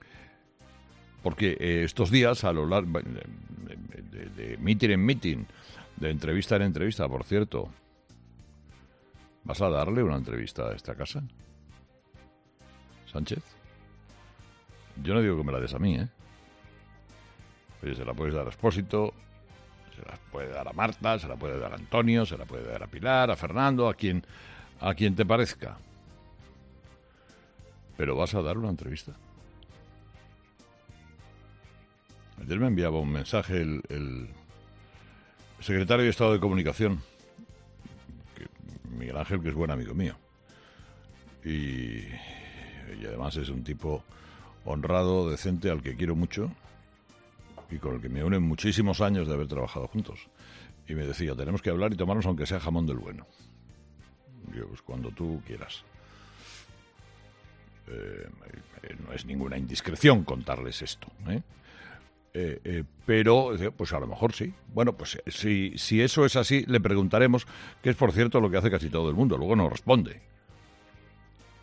Carlos Herrera ha vuelto a reiterar durante su monólogo de las 6 en Herrera en COPE su invitación al presidente del Gobierno en funciones, Pedro Sánchez, a ser entrevistado en los micrófonos de COPE durante esta precampaña electoral hasta las próximas elecciones del 10 de noviembre.